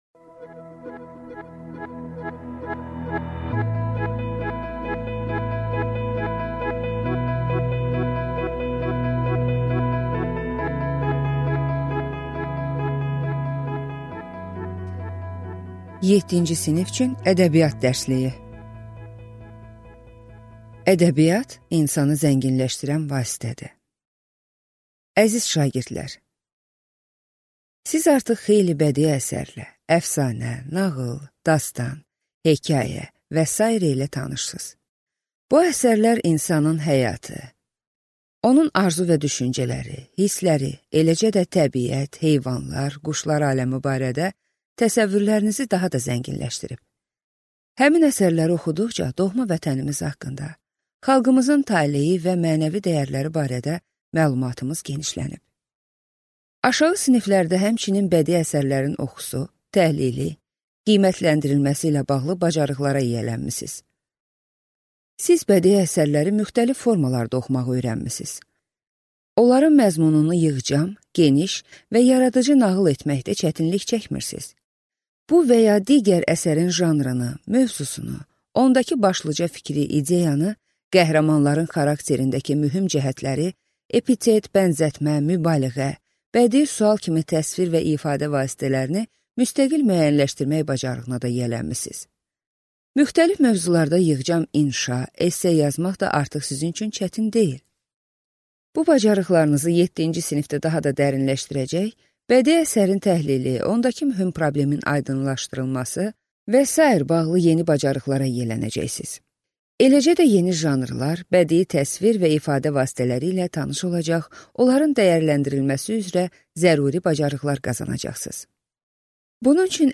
Аудиокнига Ədəbiyyat dərsliyi. 7-ci sinif | Библиотека аудиокниг